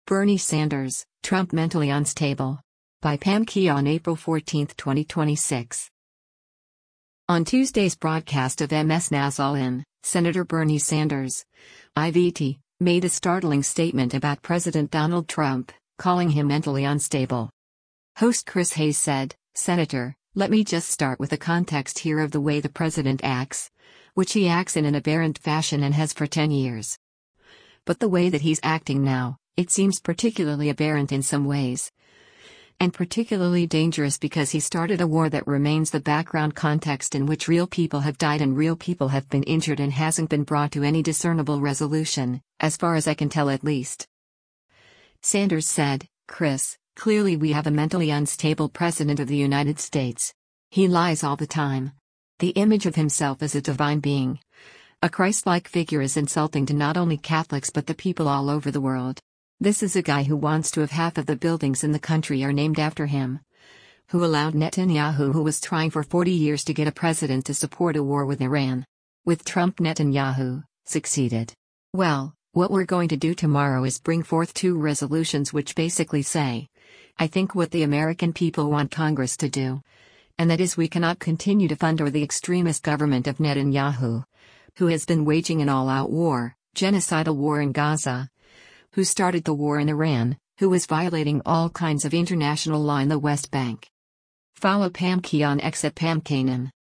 On Tuesday’s broadcast of MS NOW’s “All In,” Sen. Bernie Sanders (I-VT) made a startling statement about President Donald Trump, calling him “mentally unstable.”